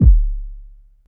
07_Kick_06_SP.wav